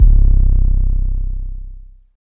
SLOPPY 808.wav